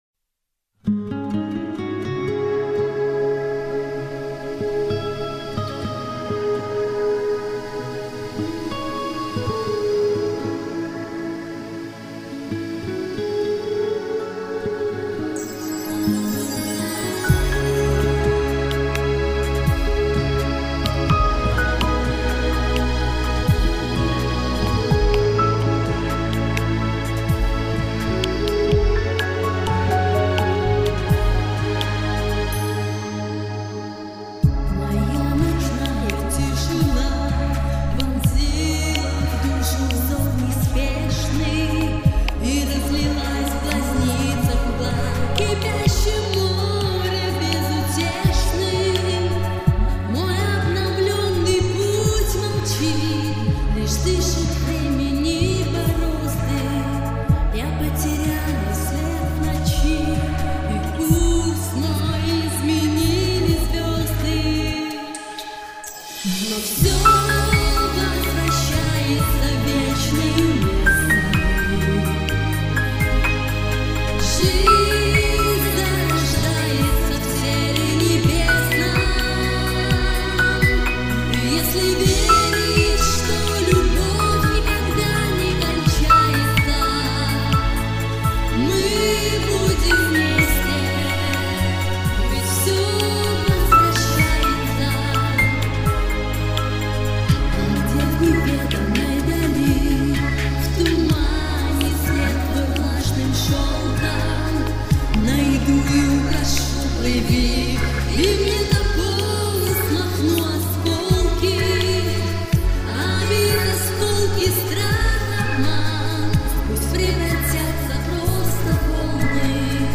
... абсолютно не грамотное сведение!